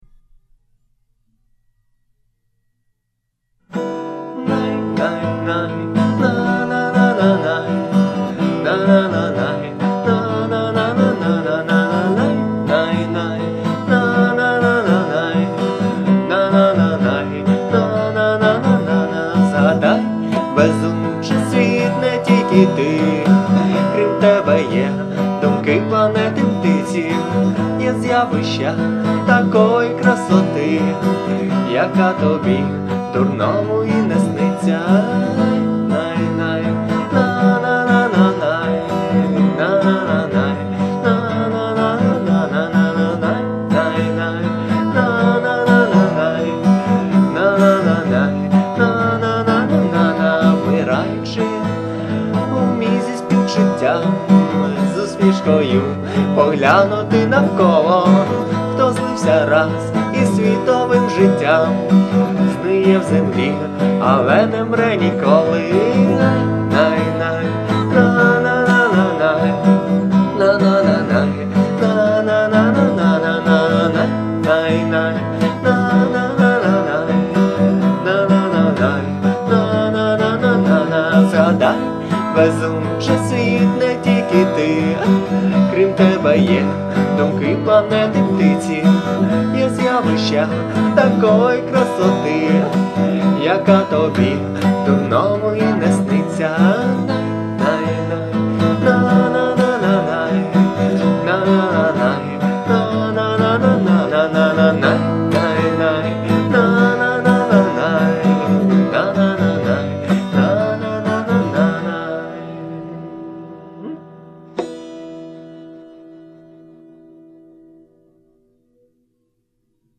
акустика